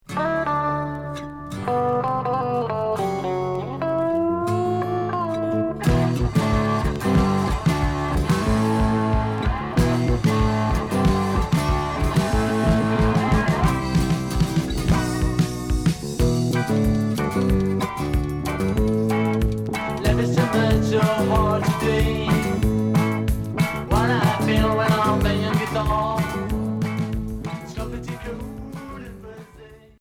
Rock reggae